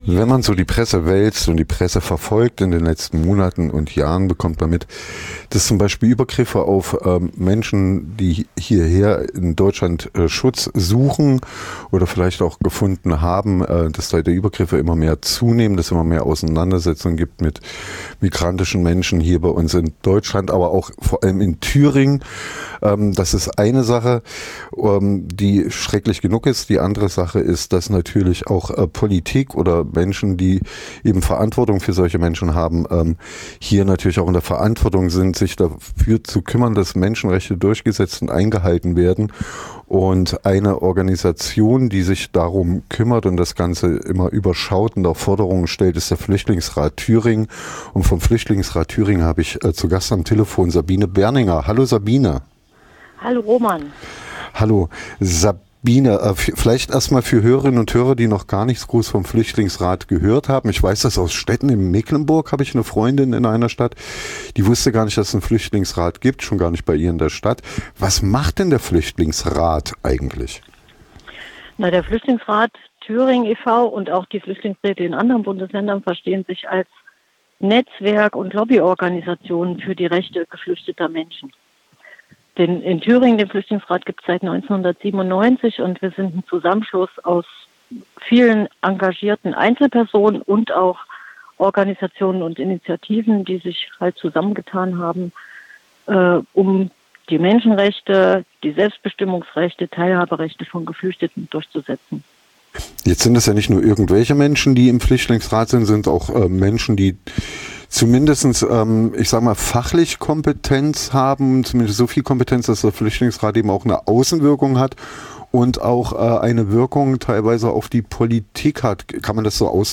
Podiumsdiskussion zu flüchtlingspolitischen Positionen zur Thüringer Landtagswahl 2024 [Neu: Interview und Veranstaltungsmitschnitt]
> Download Sabine Berninger vom Fl�chtlingsrat Th�ringen war am Donnerstag Vormittag Gespr�chspartnerin im Interview bei Radio F.R.E.I. Sie sprach �ber die Arbeit des Fl�chtlingsrats, Grundrechte von gefl�chteten Menschen und fl�chtlingspolitische Forderungen vor der Landtagswahl.